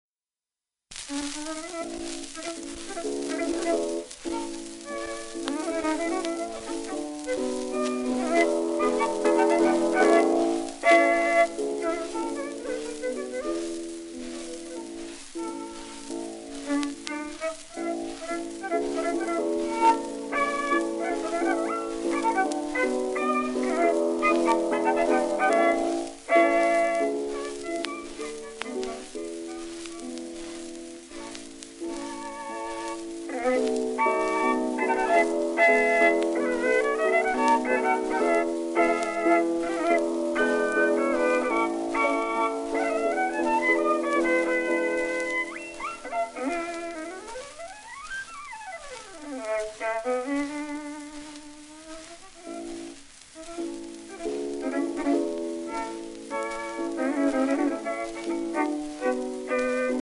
10インチ片面盤 (旧
旧 旧吹込みの略、電気録音以前の機械式録音盤（ラッパ吹込み）